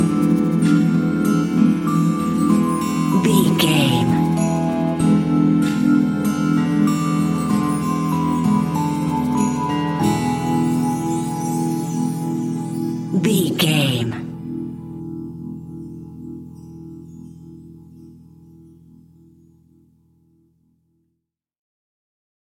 Aeolian/Minor
childrens music
instrumentals
fun
childlike
cute
happy
kids piano